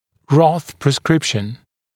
[rɔθ prɪs’krɪpʃn][рос прис’крипшн]пропись Рота